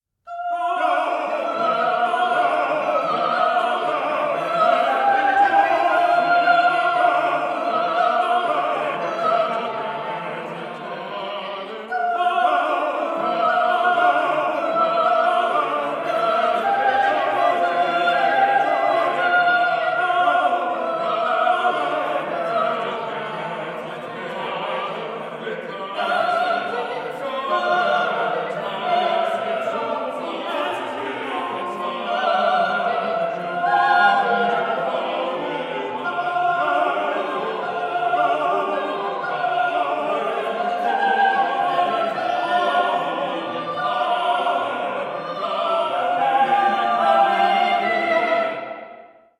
24-bit stereo
soprano
alto
tenor
baritone
bass